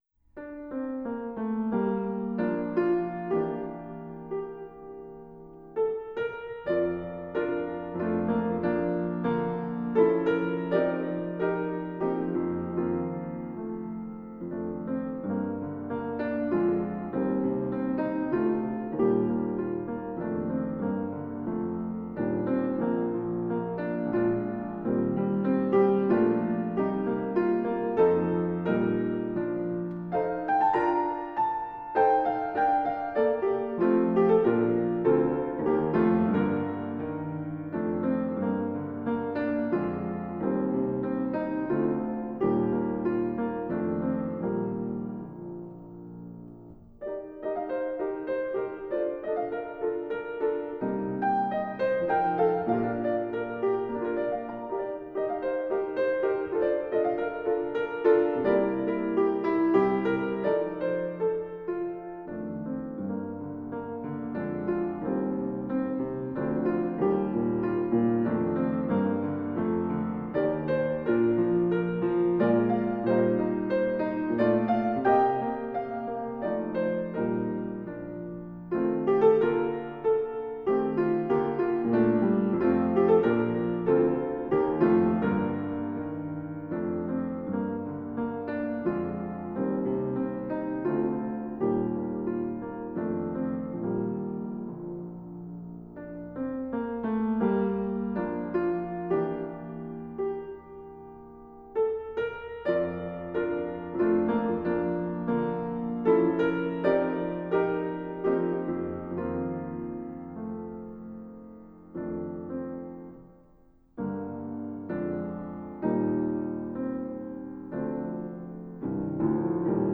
SOLO BRASS
E♭ Accompaniment Track